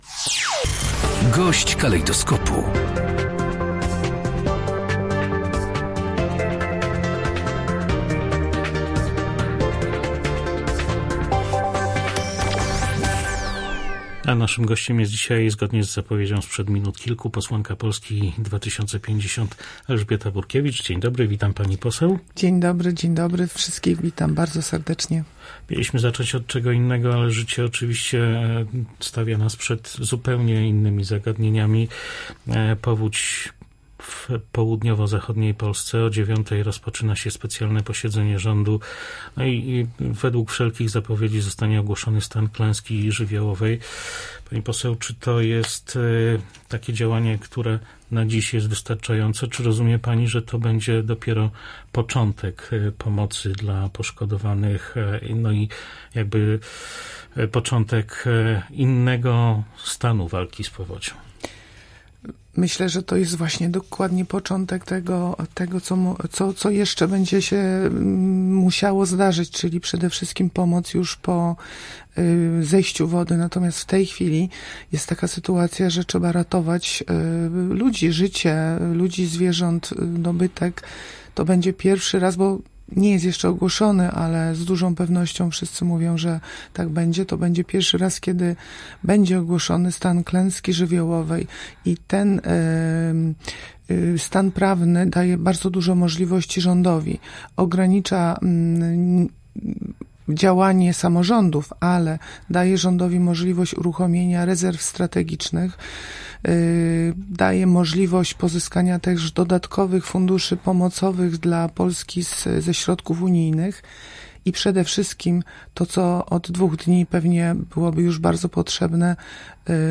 Teraz najważniejsze jest ratowanie ludzkiego życia, ale trzeba już planować kolejne kroki i organizować pomoc – uważa posłanka Polski 2050 Elżbieta Burkiewicz. Jak powiedziała na nasze antenie ogłoszenie stanu klęski żywiołowej na terenach dotkniętych powodzią daje rządowi nowe możliwości. To np. uruchomienie rezerw strategicznych, przymus ewakuacji czy możliwość ubiegania się o unijne pieniądze na usuwanie skutków żywiołu.